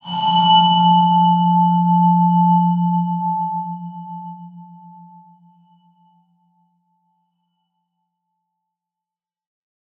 X_BasicBells-F1-pp.wav